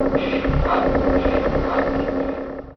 Magic-And-Spell-Sound-Effects-12 – Free Music Download For Creators
Free background Magic And Spell Sound Effects for videos, games or vlogs personal and commercial use.
Magic-And-Spell-Sound-Effects-12.mp3